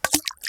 start_fishing.ogg